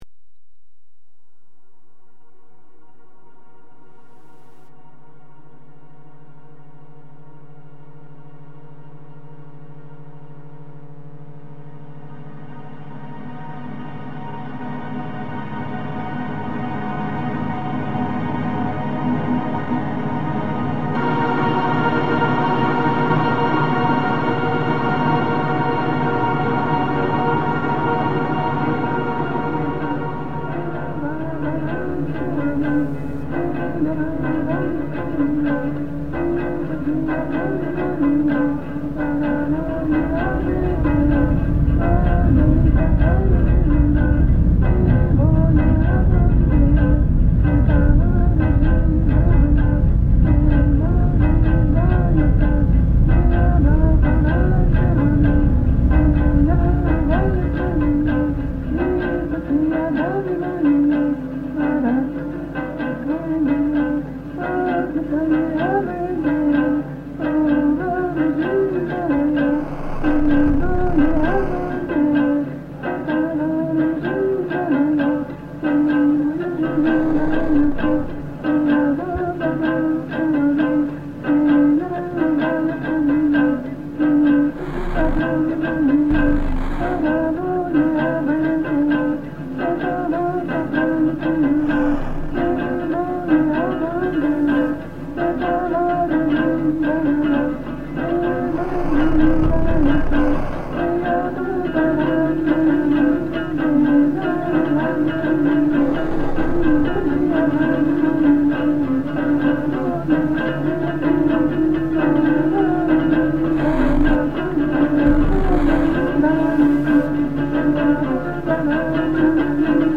The original sound source for this composition is a selection of Nuristani songs. The voice is accompanied by a plucked and bowed string instrument as a type of rhythmic drone.